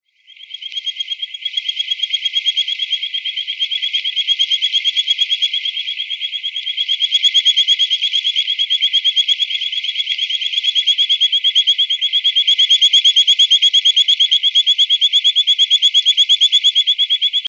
Play Especie: Boophis luteus Género: Boophis Familia: Mantellidae Órden: Anura Clase: Amphibia Título: The calls of the frogs of Madagascar.
Localidad: Madagascar: Pico St. Louis, Tolagnaro
Tipo de vocalización: Llamadas de apareamiento
22_3 Boophis Luteus.mp3